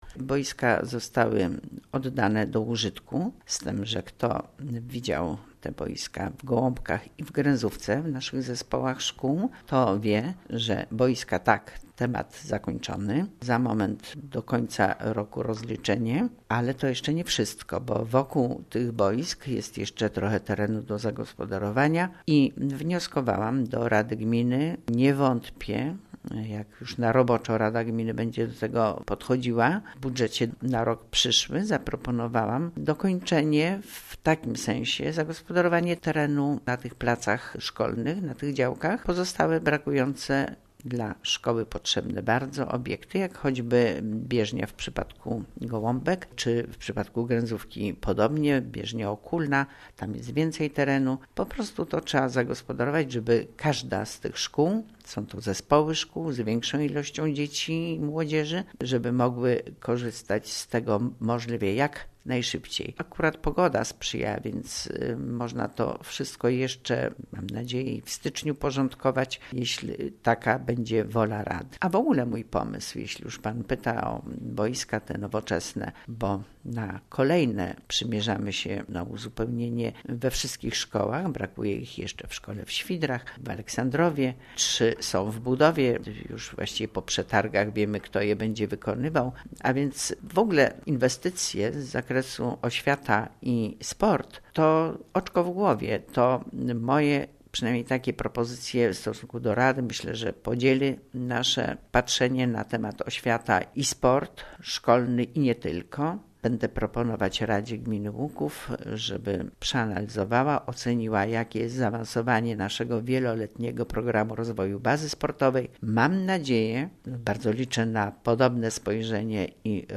Kilkakrotnie informowali�my na naszej antenie o budowie dw�ch boisk sportowych ze sztuczn� traw� w Gminie �uk�w. O tym na jakim etapie s� obecnie te inwestycje informuje:
W�jt Gminy �uk�w Kazimiera Go�awska